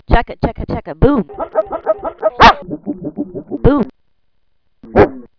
The, uh... "music" is something I made up with an old sound editor.  That's my voice, rendered somewhat higher than it really is, and the voice of my dog Red.
When a dog's bark is played backwards you get that sound like a disc jockey abusing a vinyl record.
boomboom.wav